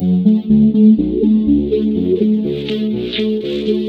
Deutschlander F# 123.wav